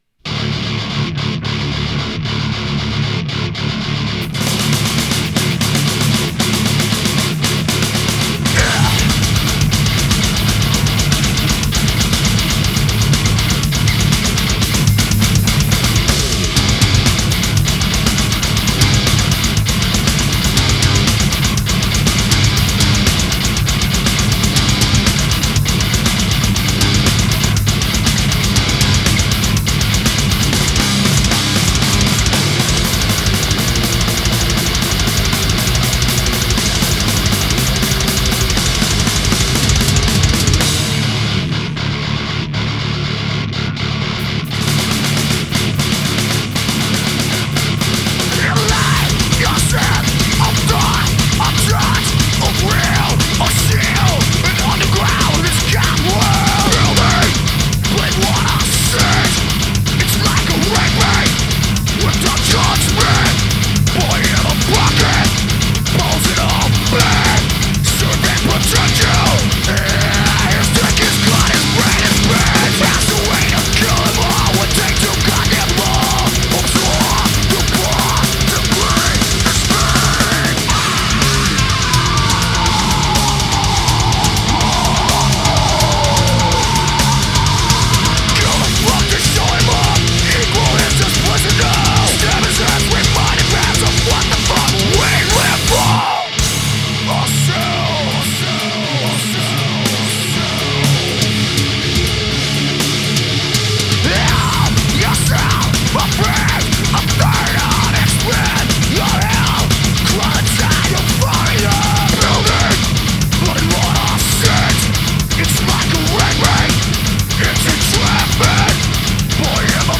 Groove metal